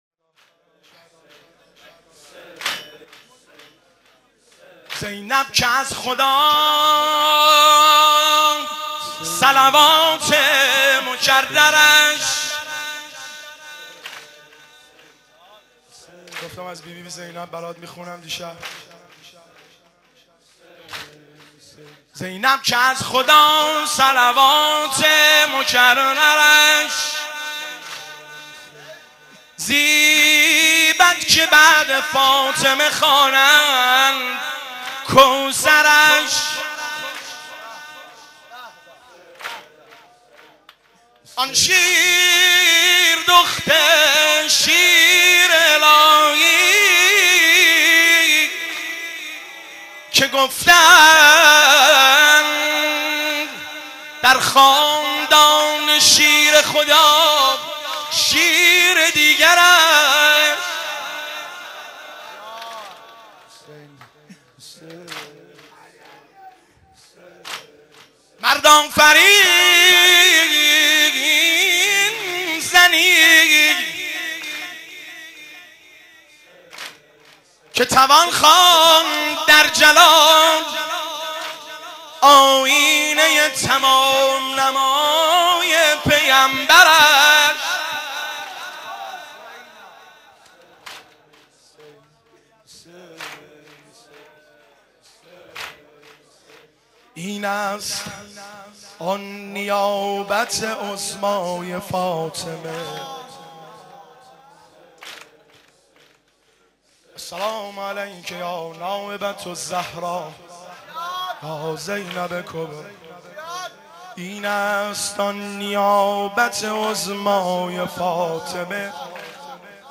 قالب : زمزمه